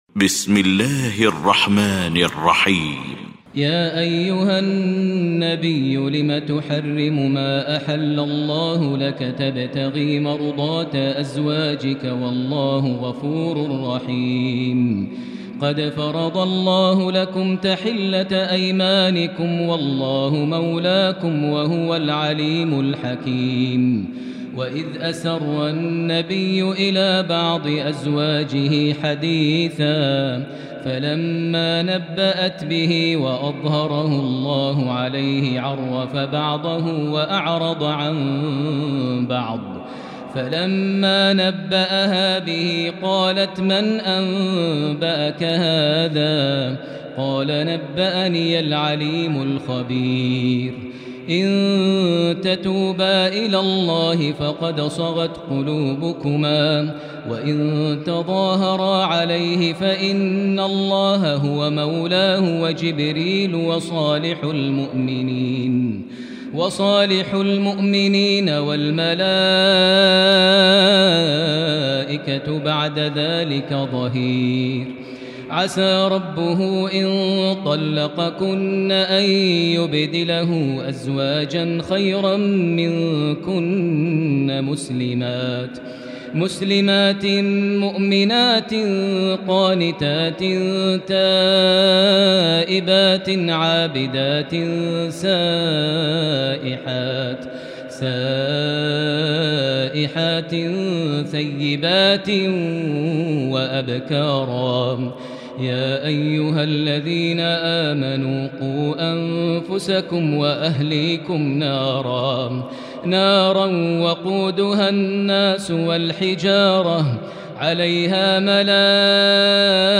المكان: المسجد الحرام الشيخ: فضيلة الشيخ ماهر المعيقلي فضيلة الشيخ ماهر المعيقلي التحريم